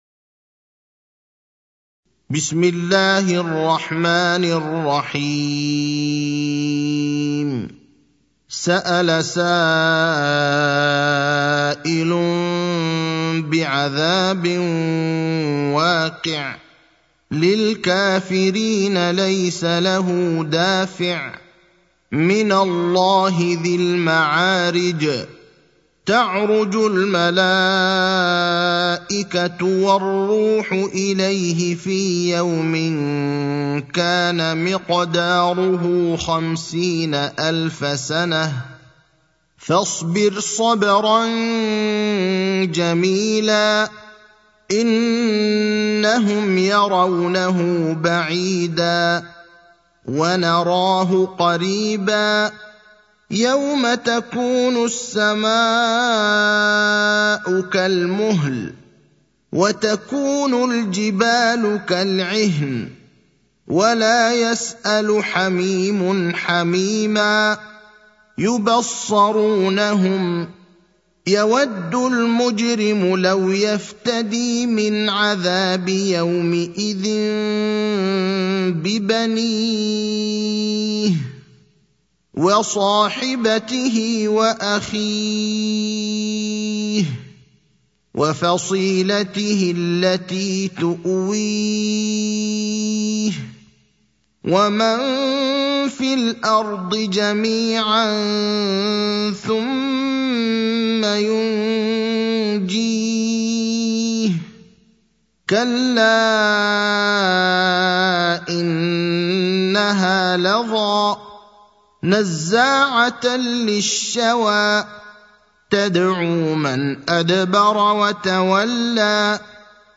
المكان: المسجد النبوي الشيخ: فضيلة الشيخ إبراهيم الأخضر فضيلة الشيخ إبراهيم الأخضر المعارج (70)